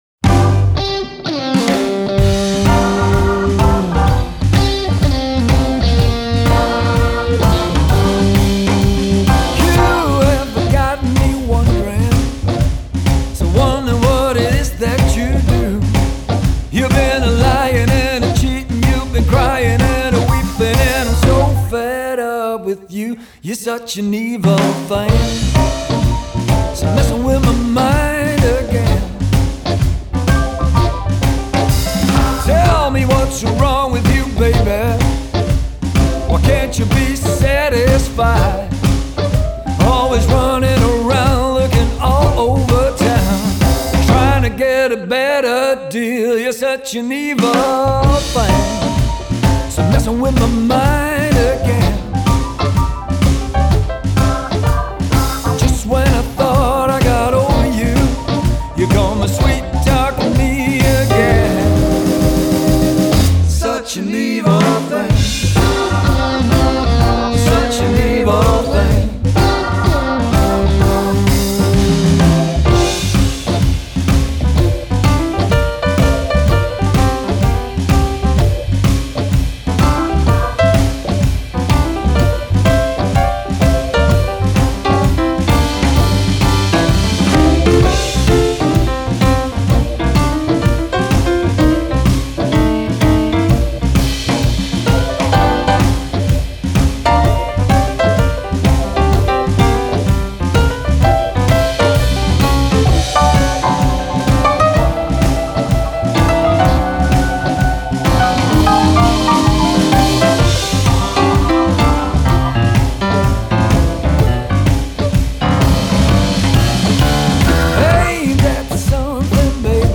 За вокал в группе отвечают сразу трое